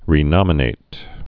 (rē-nŏmə-nāt)